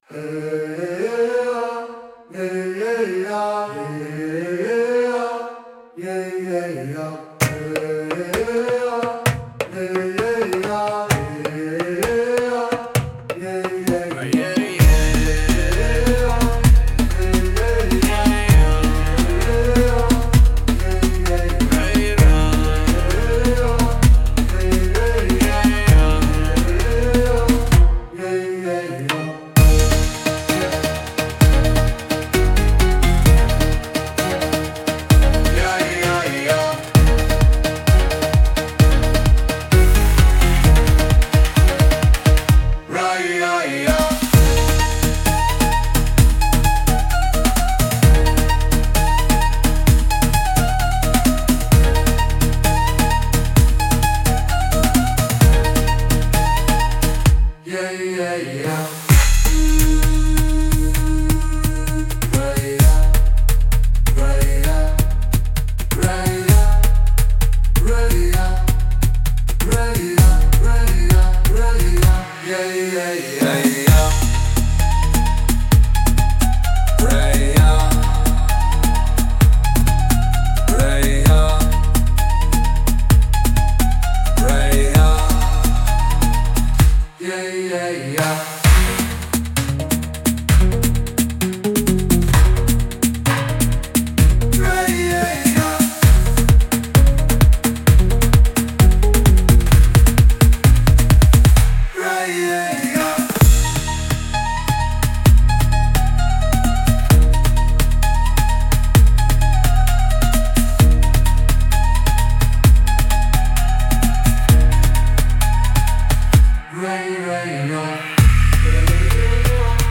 An upbeat tribal track defined by intense, pounding drums.
激しく打ち鳴らされる太鼓（ドラム）の音が、最高にクールでエネルギッシュです。
野性味あふれるリズムに乗って、力強くダイナミックに踊りたい選手にぴったり。